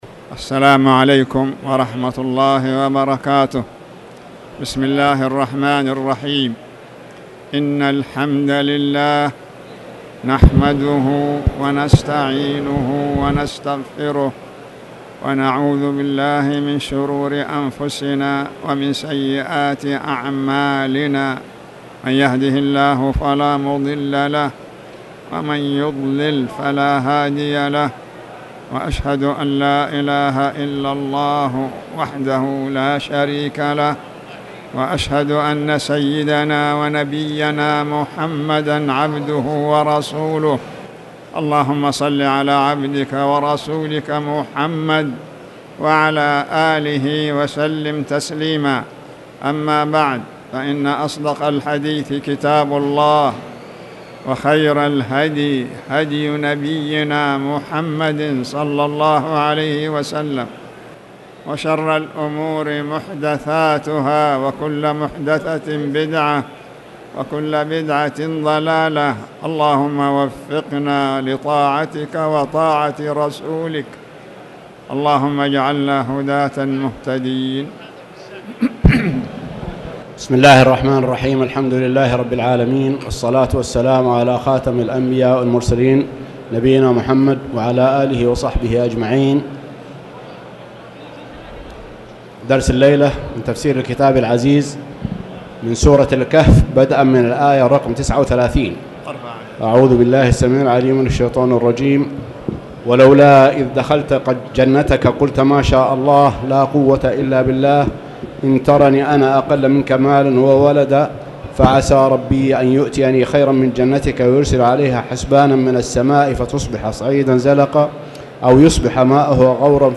تاريخ النشر ٩ محرم ١٤٣٨ هـ المكان: المسجد الحرام الشيخ